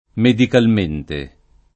medikalm%nte] l’avv.